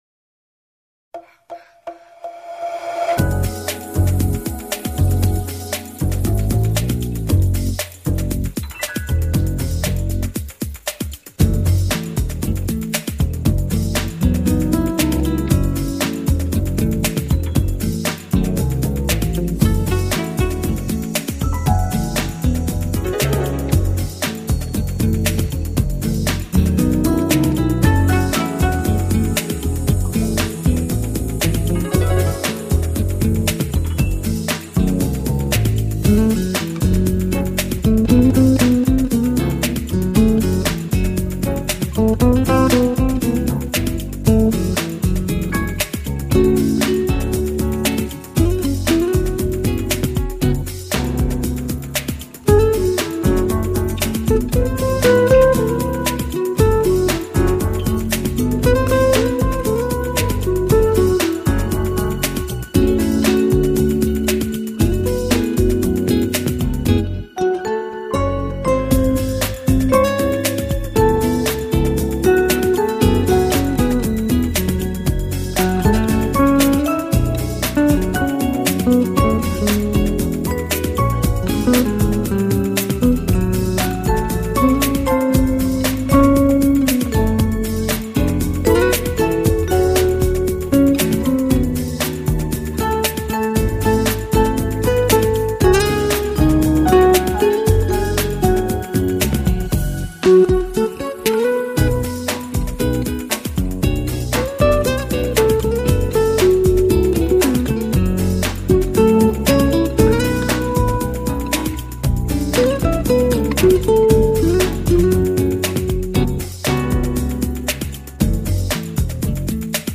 音乐风格: Smooth Jazz